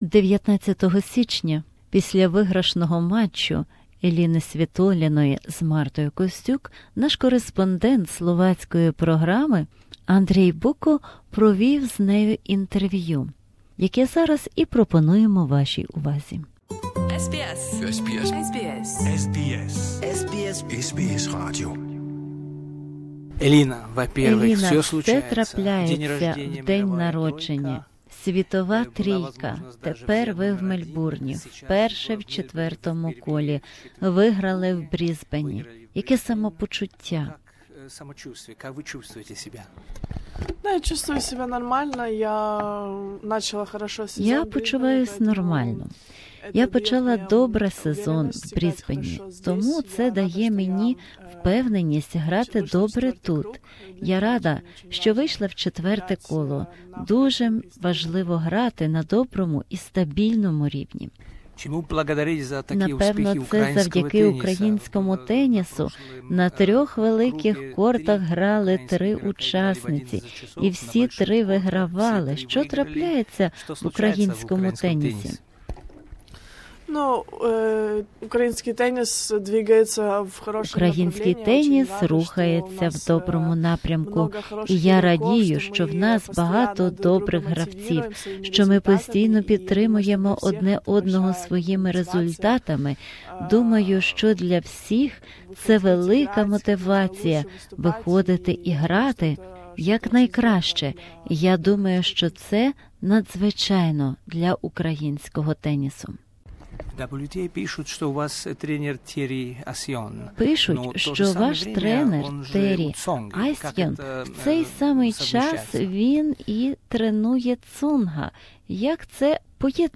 Interview with Elina Svitolina